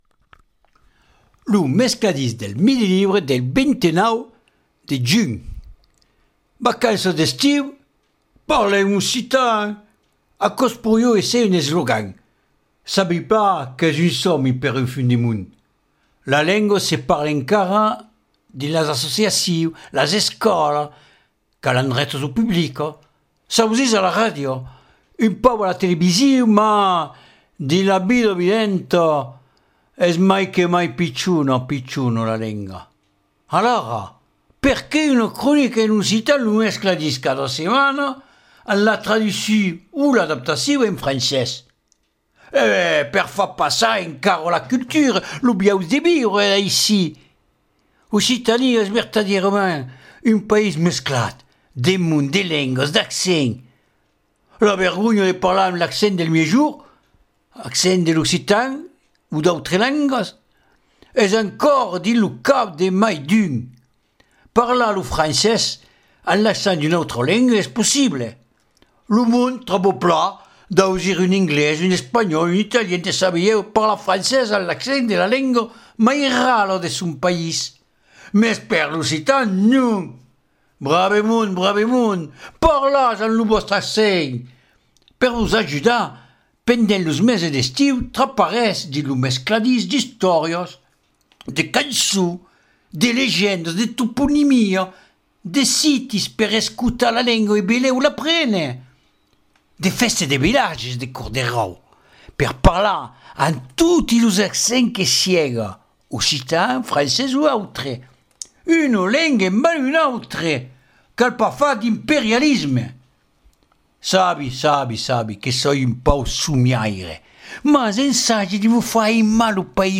Alara perqué una cronica en occitan « Lo mescladís » cada setmana amb la traducion o l’adaptacion en francés ?